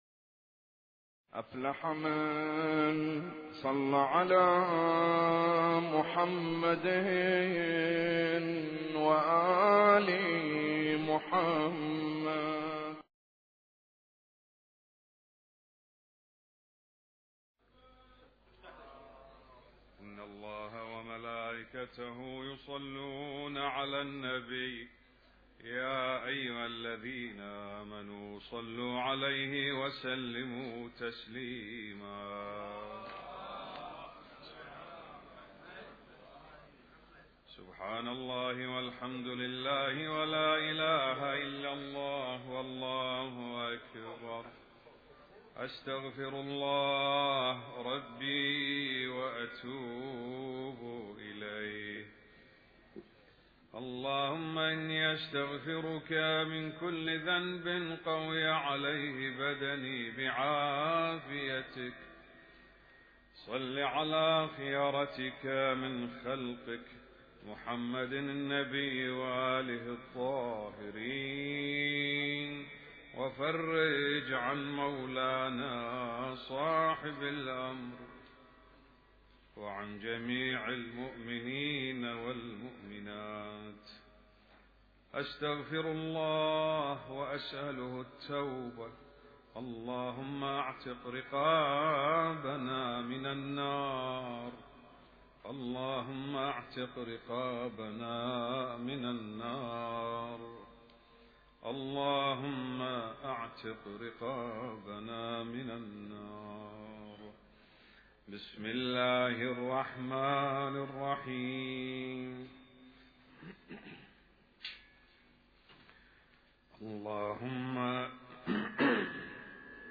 اسم التصنيف: المـكتبة الصــوتيه >> الادعية >> ادعية ليالي القدر